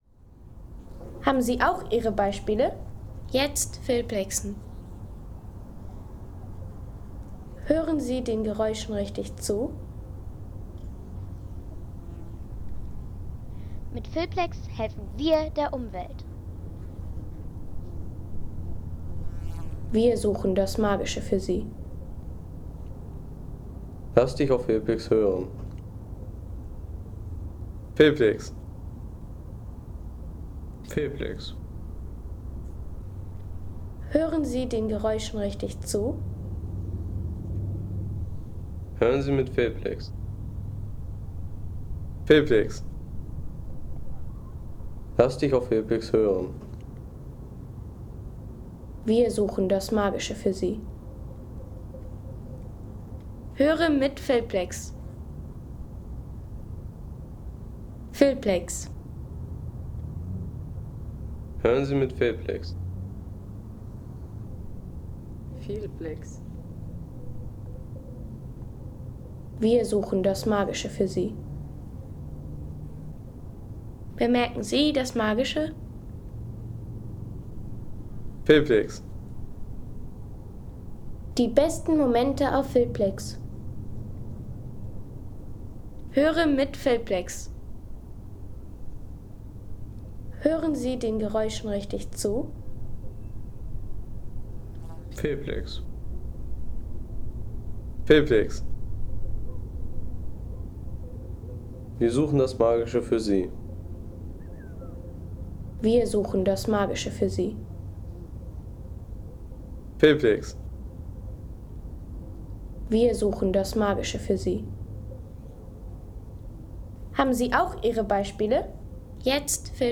Härmelekopf Home Sounds Landschaft Berge Härmelekopf Seien Sie der Erste, der dieses Produkt bewertet Artikelnummer: 227 Kategorien: Landschaft - Berge Härmelekopf Lade Sound....